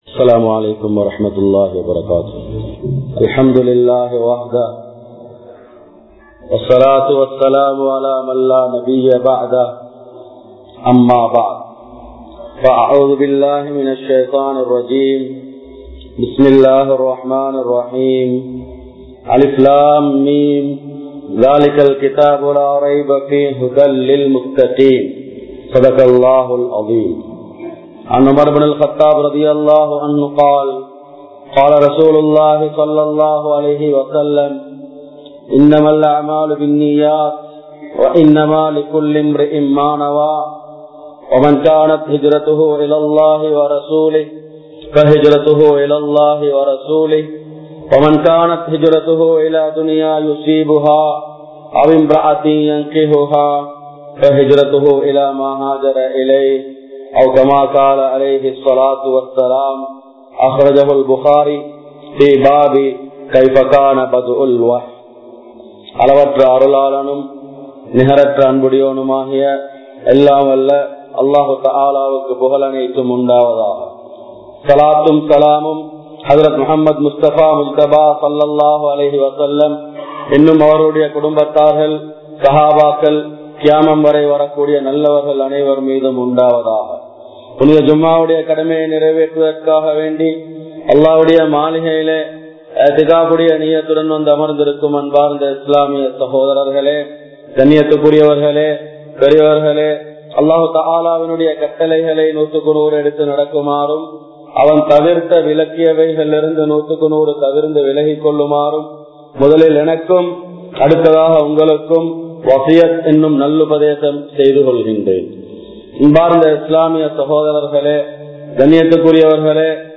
Al Quran Koorum Naahareeham (அல்குர்ஆன் கூறும் நாகரீகம்) | Audio Bayans | All Ceylon Muslim Youth Community | Addalaichenai